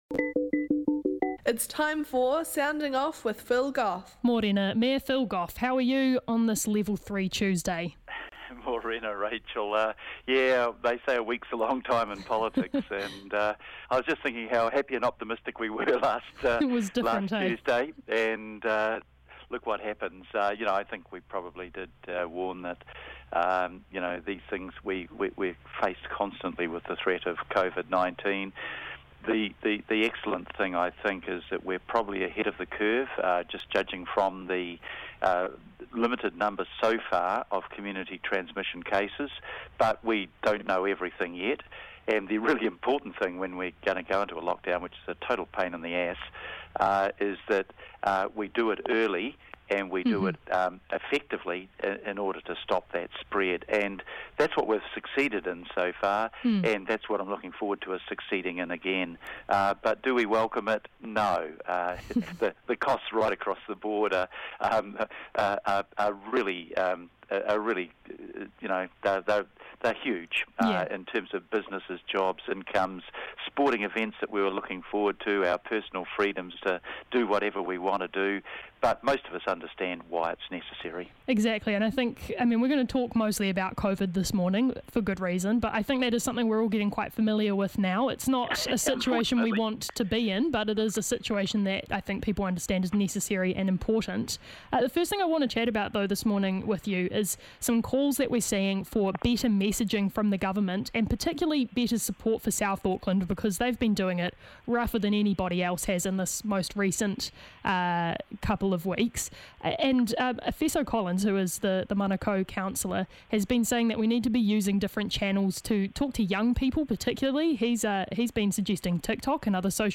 Our weekly catch up with the His Worship the Mayor of Auckland, the Honourable Phil Goff.